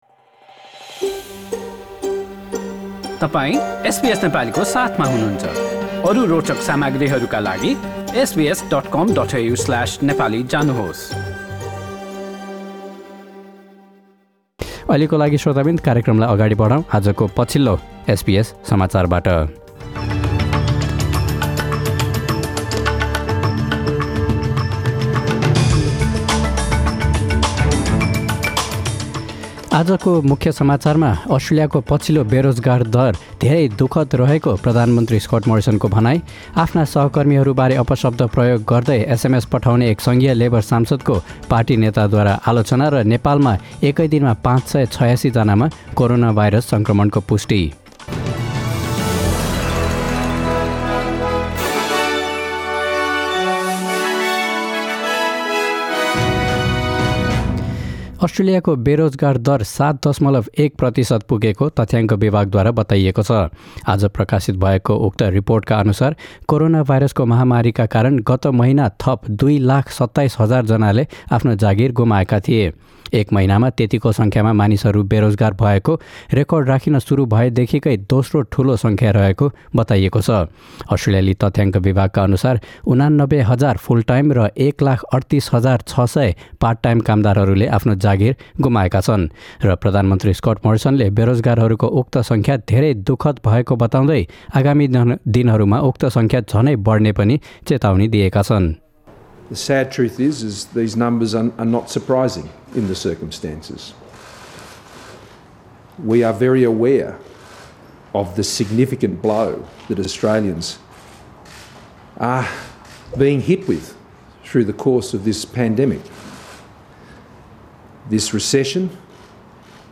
एसबीएस नेपाली अस्ट्रेलिया समाचार: बिहिवार १८ जुन २०२०